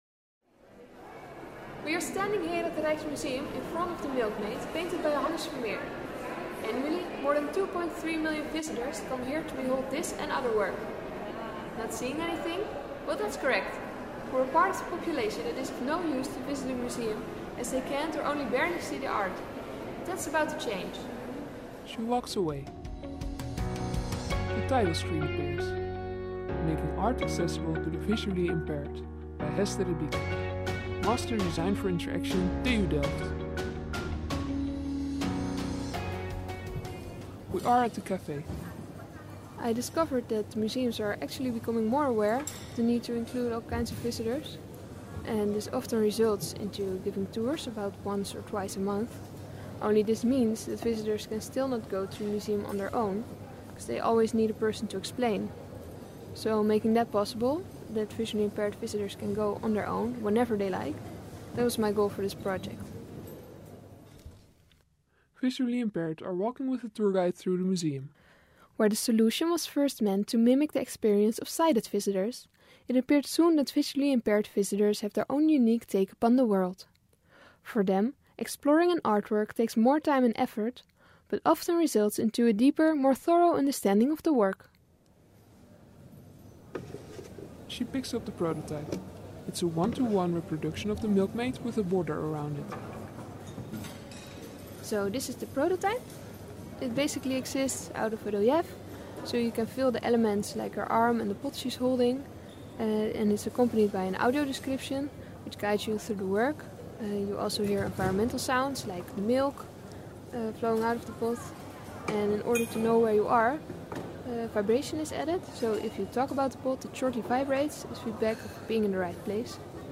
It is accompanied auditory by narration.
In between the story, complementary sounds are added, like bread and milk.
The intermediate sounds were received positive as well, for they added to the atmosphere.